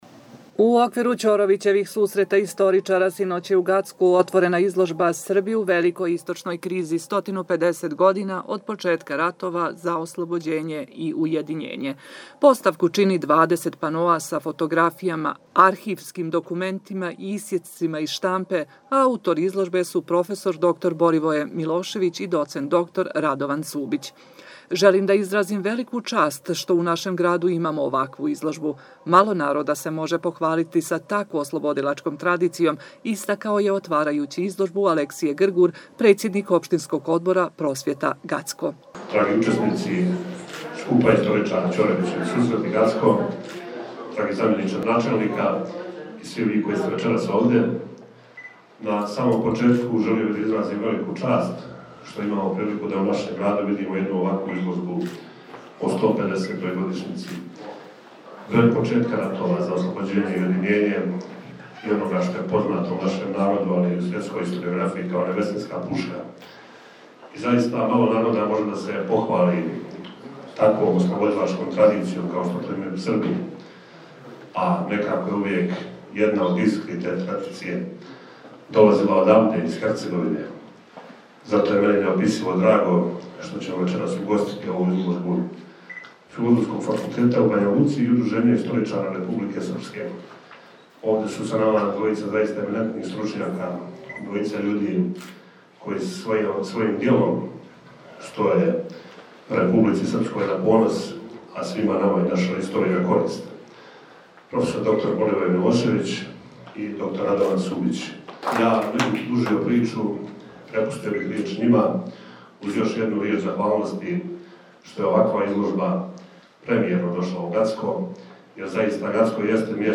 U okviru Ćorovićevih susreta istoričara sinoć je u Gacku otvorena izložba „ Srbi u Velikoj istočnoj krizi – 150 godina od početka ratova za oslobođenje i ujedinjenje“.